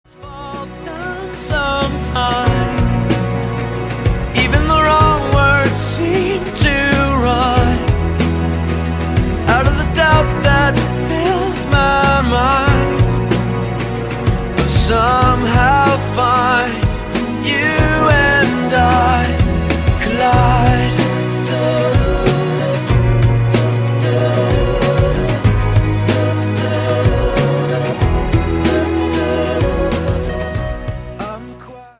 • Качество: 160, Stereo
мужской вокал
Pop Rock
романтичные
Soft rock
баллада